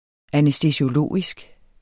Udtale [ anεsdəsioˈloˀisg ]